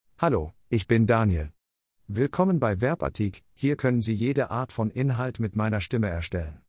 Daniel — Male German KI-Stimme
Daniel ist eine male KI-Stimme für German.
Stimmprobe
Male